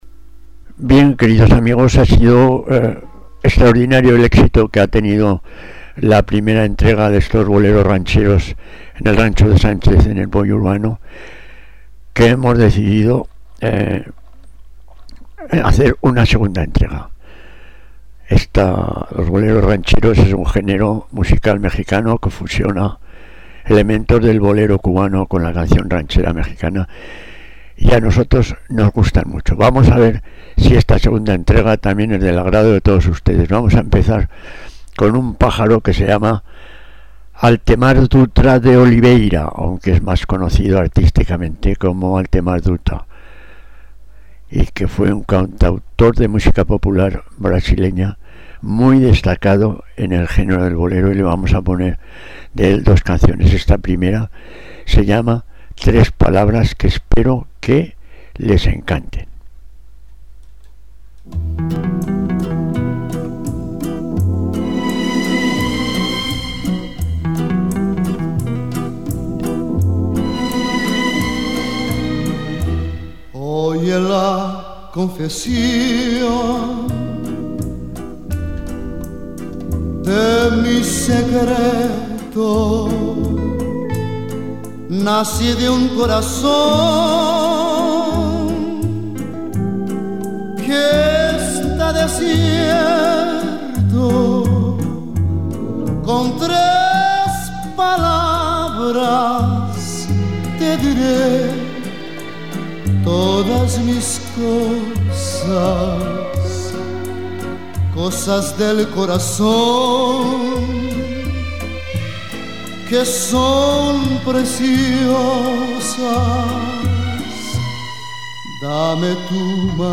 «boleros rancheos»
el rey del bolero